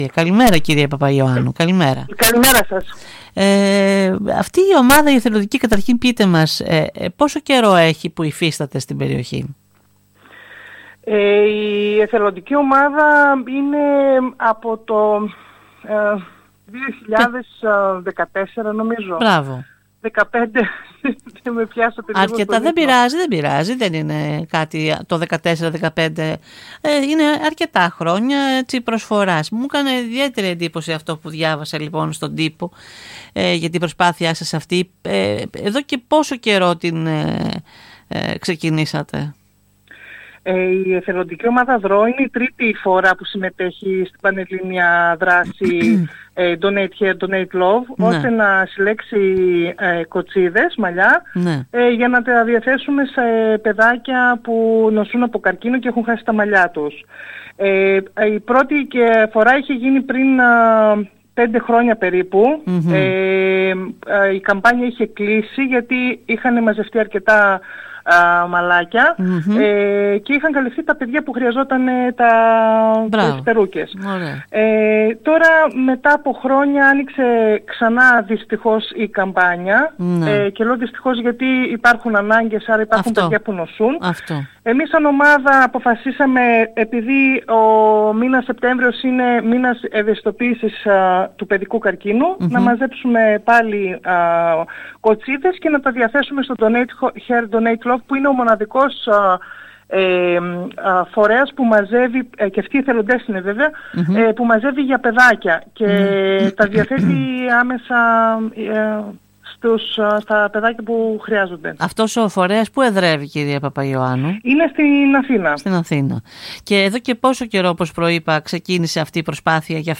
Μιλώντας στην ΕΡΤ Ορεστιάδας